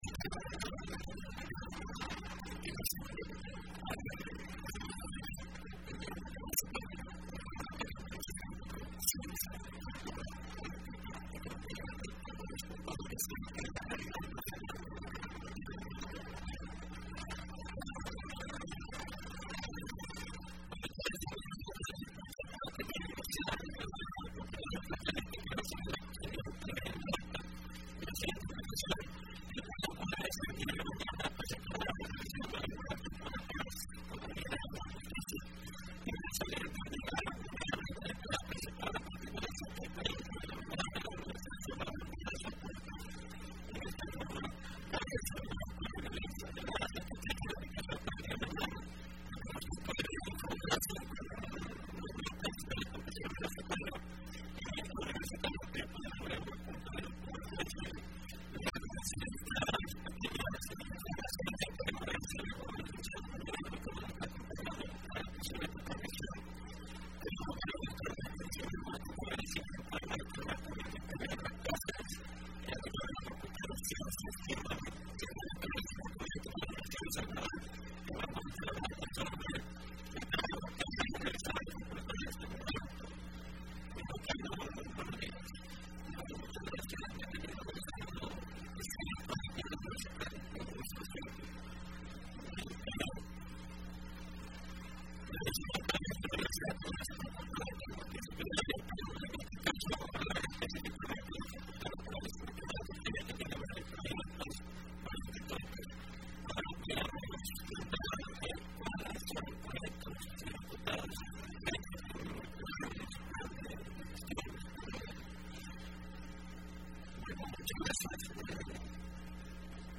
Entrevista Opinión Universitaria (25 de marzo 2015): Balance laboral y logros de la Facultad de Ciencias Naturales y Matemática de la Universidad de El Salvador.